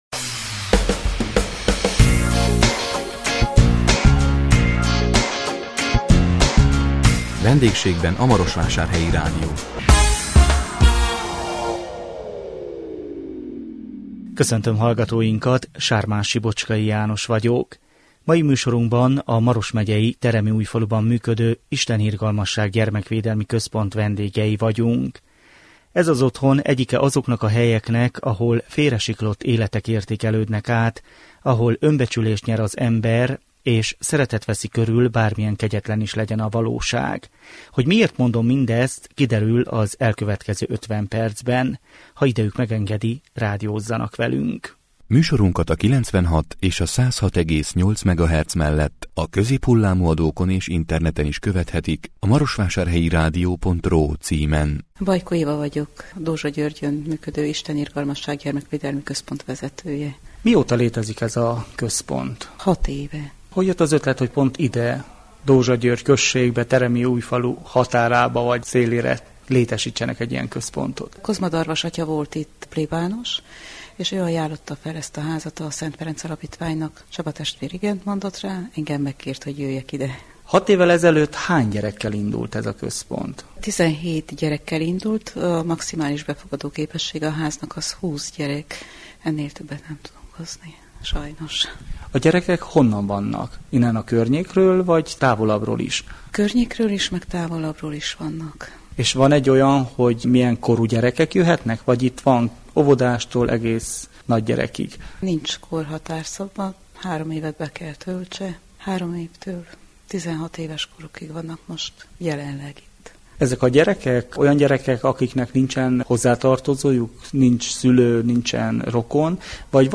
A 2016 december 15-én jelentkező Vendégségben a Marosvásárhelyi Rádió című műsorunkban a Maros megyei Teremiújfaluban működő Isten Irgalmasság Gyermekvédelmi Központ vendégei voltunk. Ez az otthon egyike azoknak a helyeknek, ahol félresiklott életek értékelődnek át, ahol önbecsülést nyer az ember, és szeretet veszi körül bármilyen kegyetlen is legyen a valóság. Meghívottainkkal erről beszélgettünk.